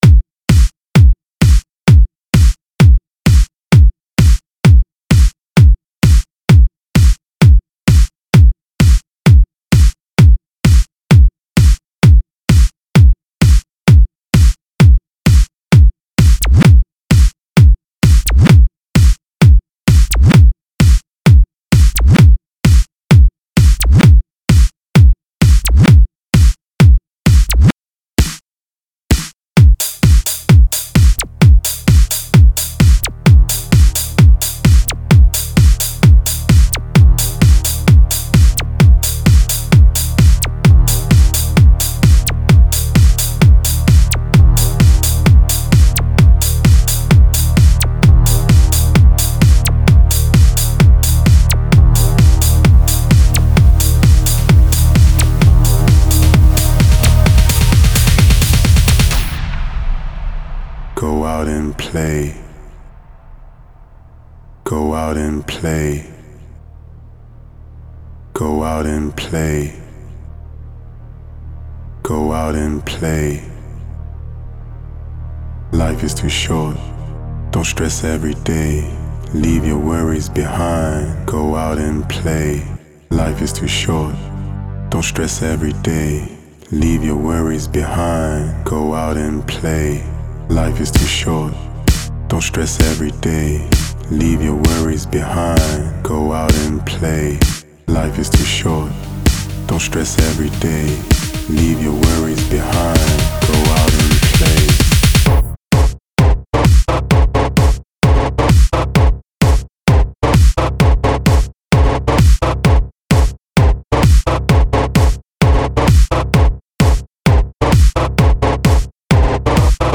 Жанр:Electro-house